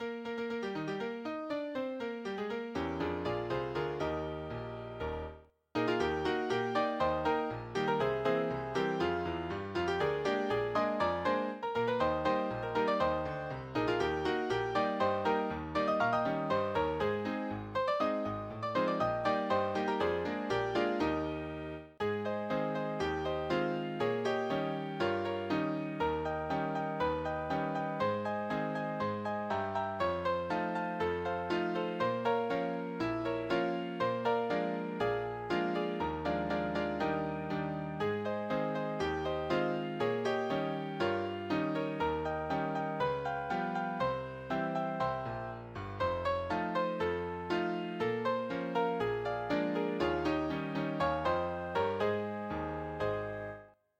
we were able to use a program called SharpEye to generate the audio.